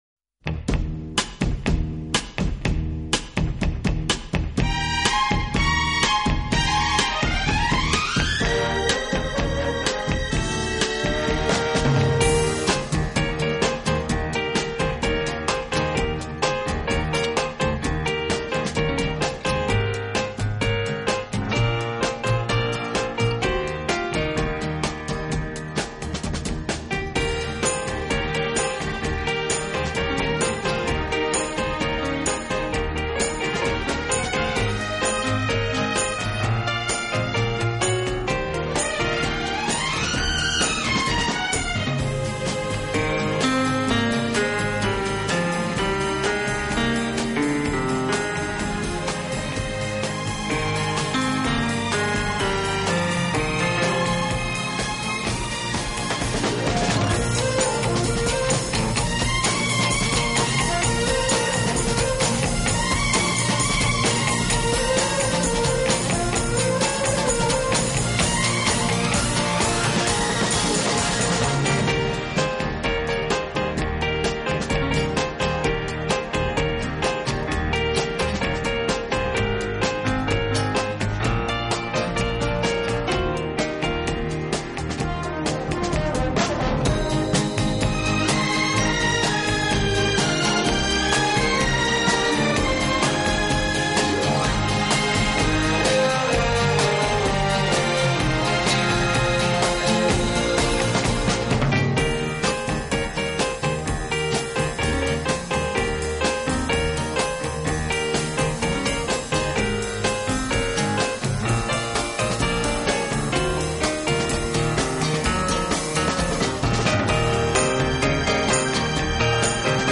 【双钢琴】